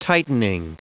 Prononciation du mot tightening en anglais (fichier audio)
Prononciation du mot : tightening